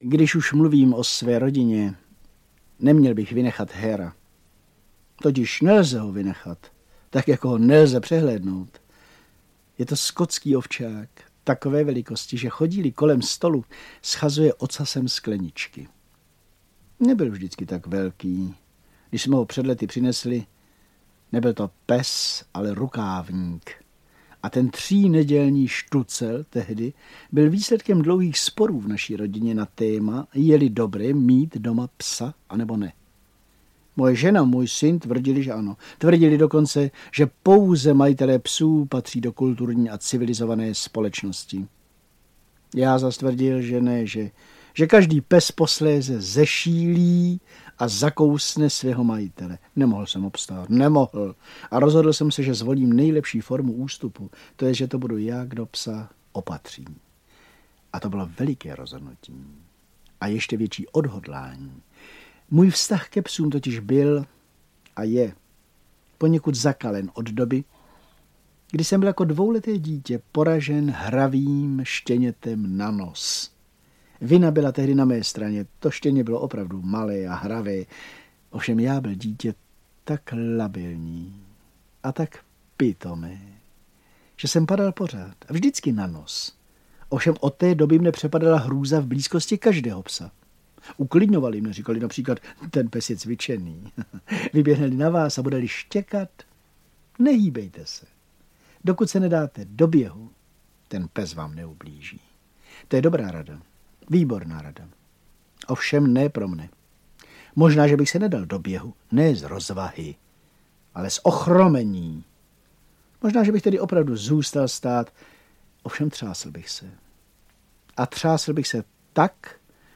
Audiokniha
• Čte: Miroslav Horníček